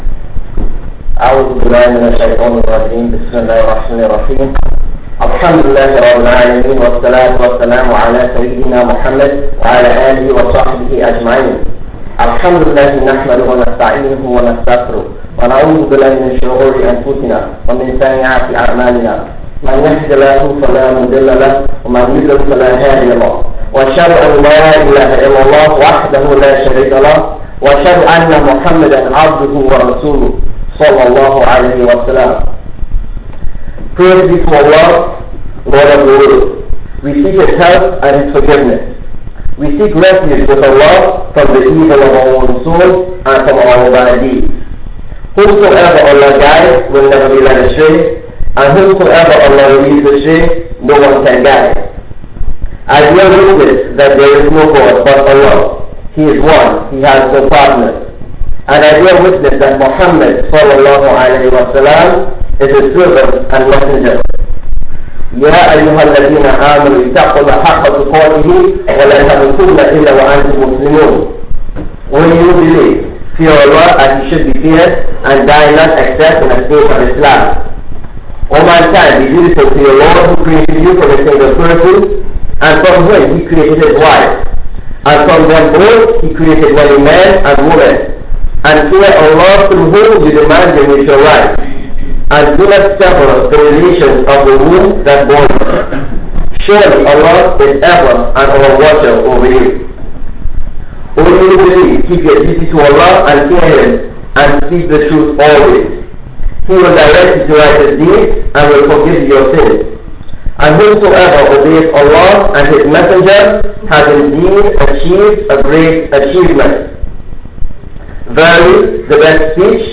Jumu'ah Khutbah: Remember Allah: The Key to Success (Temple MSA - 4/29/11 | Masjid Quba - 5/13/11)